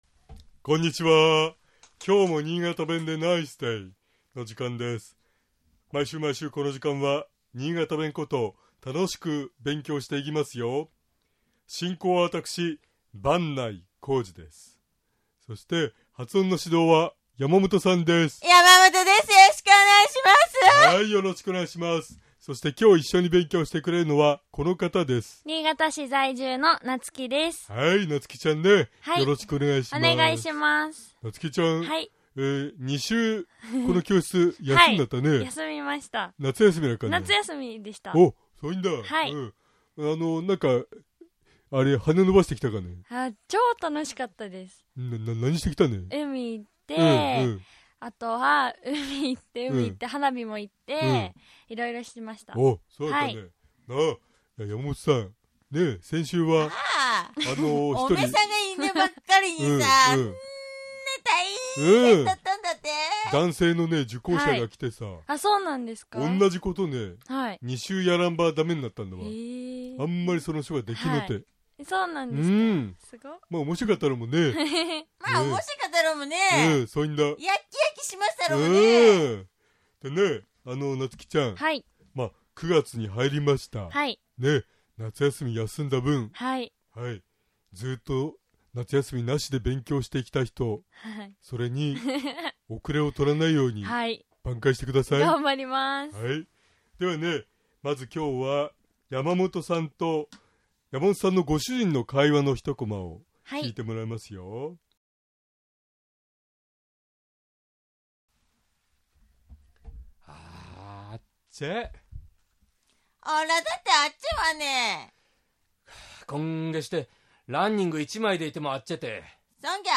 まず、「暑い」と言う場合、新潟弁では「あっちぇ」と発音します。
尚、このコーナーで紹介している言葉は、 主に新潟市とその周辺で使われている方言ですが、 それでも、世代や地域によって、 使い方、解釈、発音、アクセントなどに 微妙な違いがある事を御了承下さい